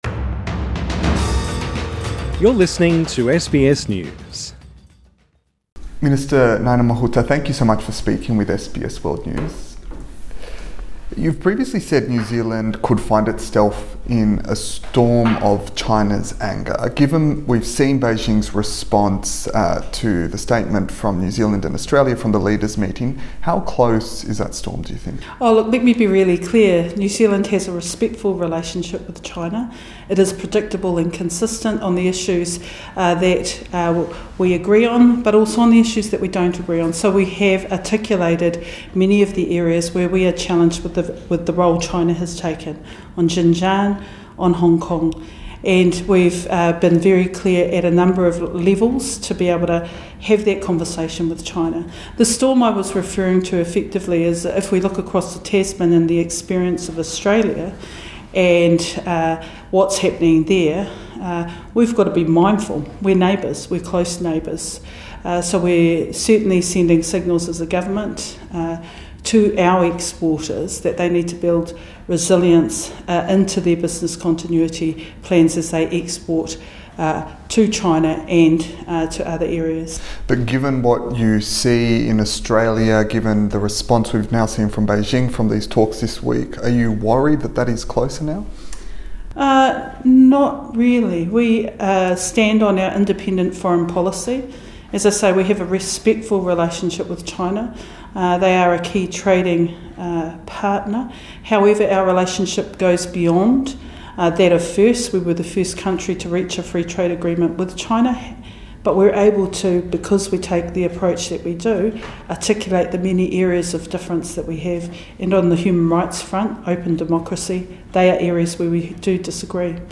New Zealand's Foreign Minister speaks to SBS News